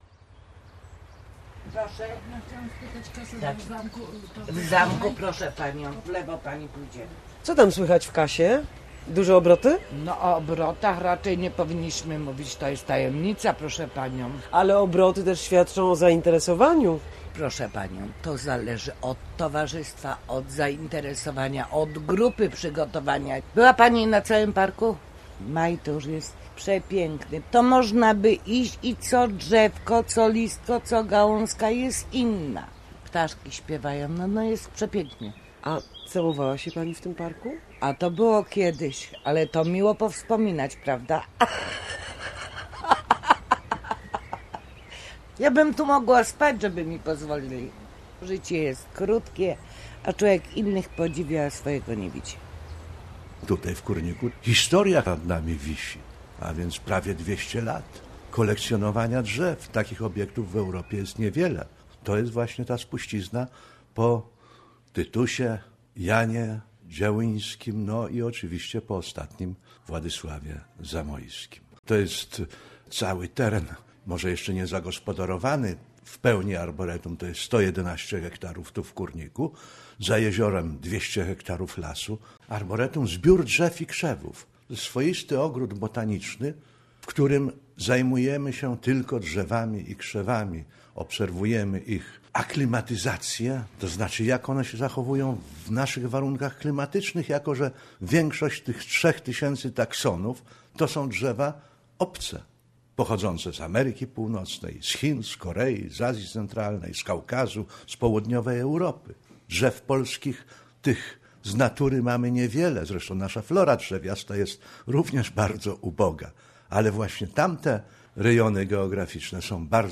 Reportaż: "Zielona plama" Sobota 14.15 polecamy Blisko dwieście lat historii, największy pod względem gatunków i odmian roślin park dendrologiczny w Europie Środkowo - Wschodniej, tylko 22 km od centrum Poznania. Zabierzemy Was w to niezwykłe miejsce na spacer.